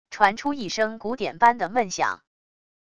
传出一声鼓点般的闷响wav音频